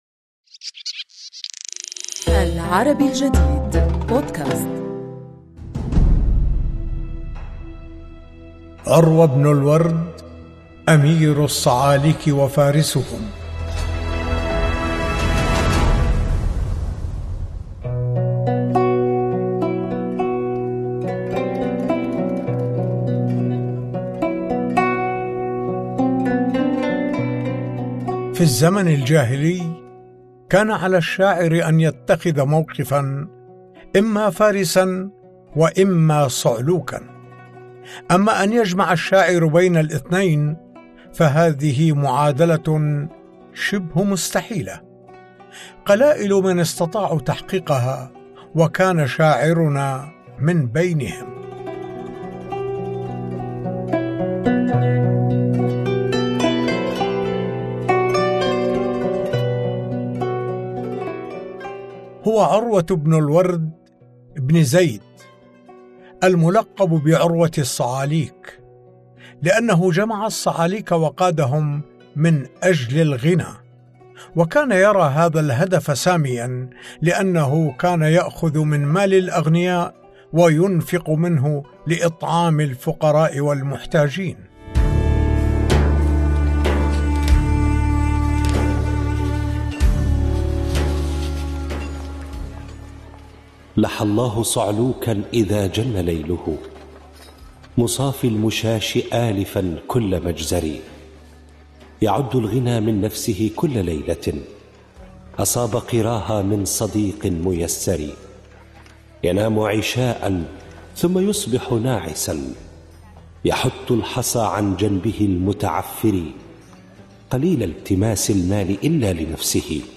الراوي